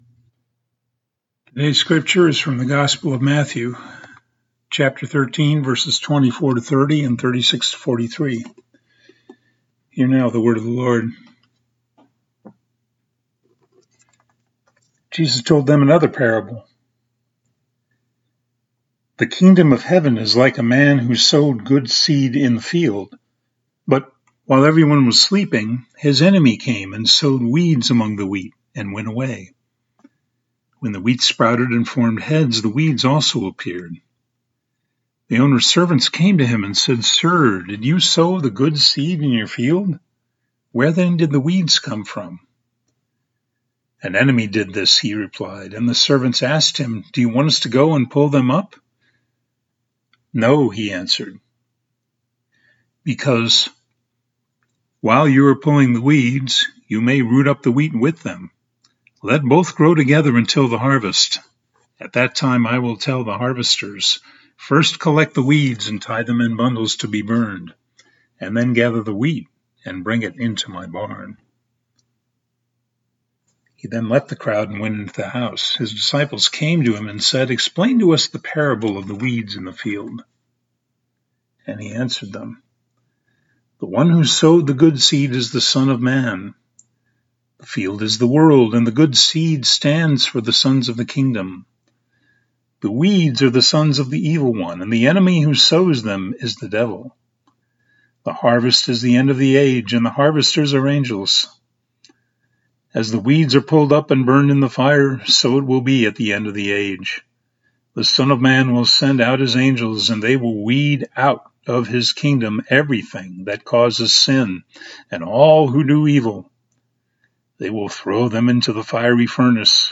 Scripture